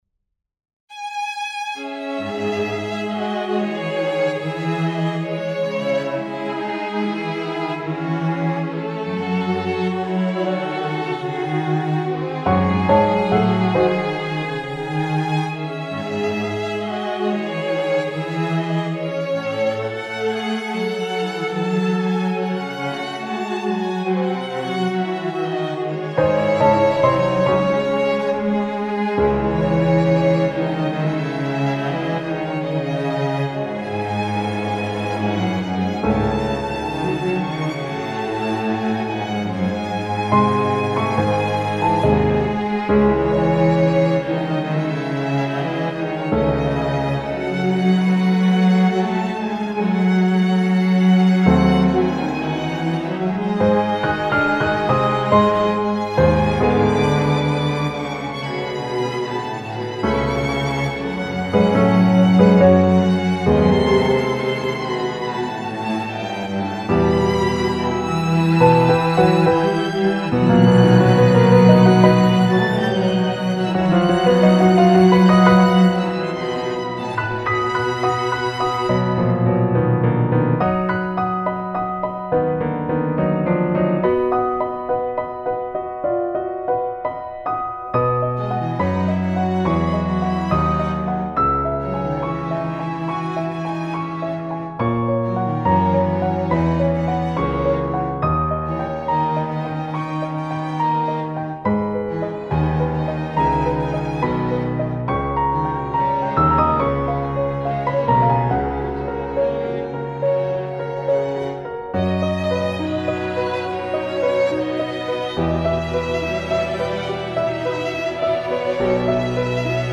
Op110 Movement2 Andante cantabile - Chamber Music - Young Composers Music Forum